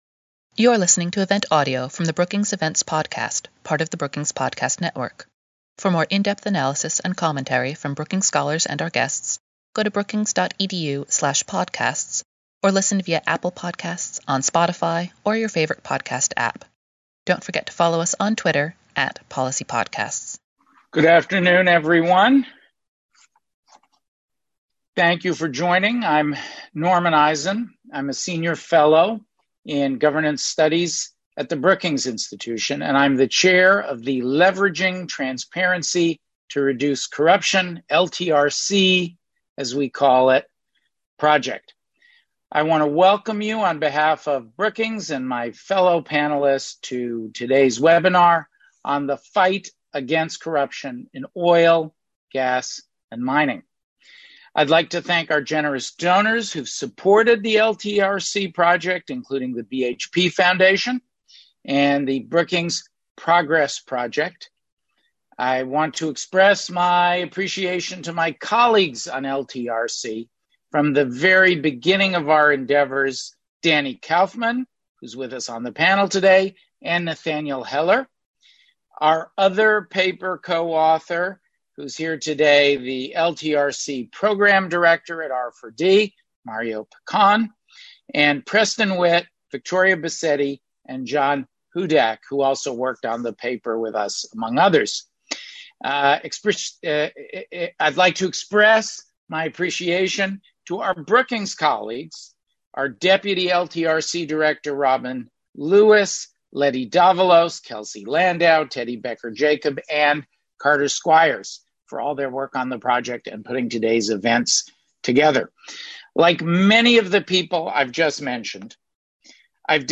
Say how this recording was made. On July 1, Brookings hosted a webinar to explore key takeaways from a report on anti-corruption initiatives and solutions to the challenges of corruption along the natural resource value chain globally.